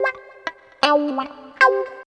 95 GTR 2  -L.wav